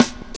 Snare (42).wav